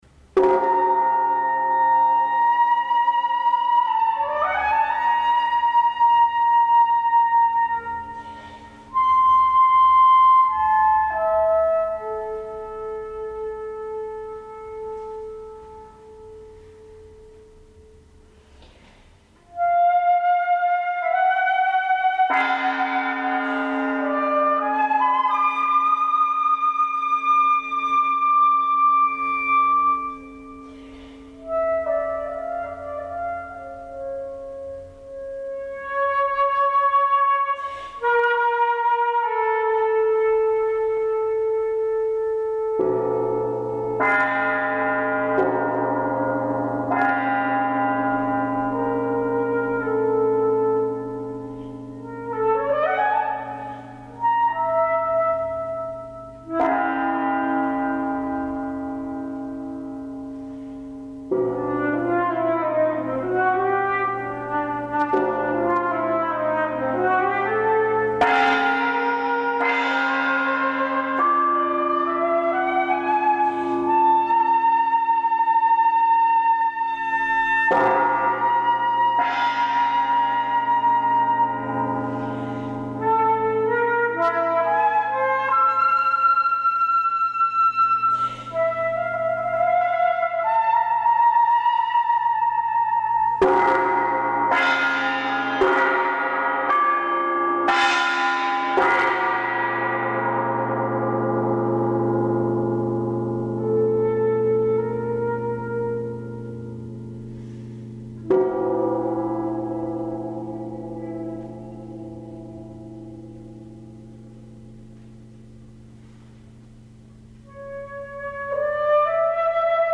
percussion Duration